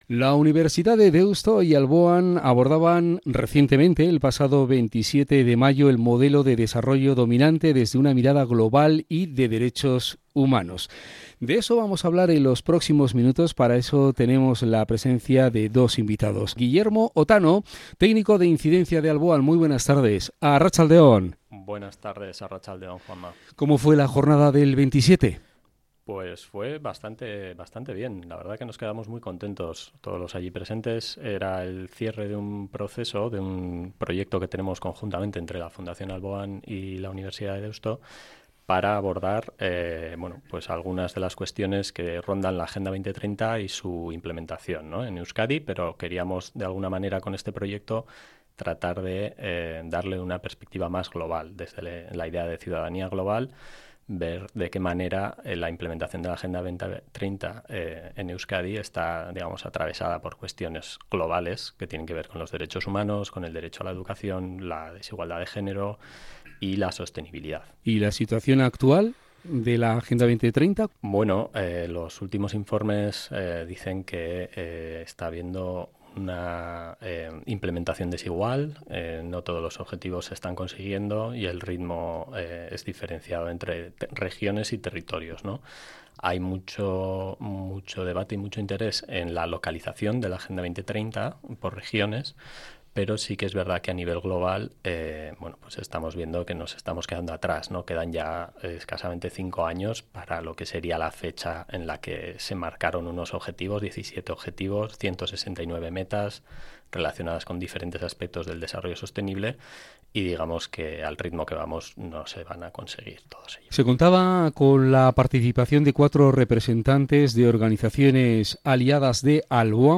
II Jornada del proyecto “Euskaditik Mundura” en la Universidad de Deusto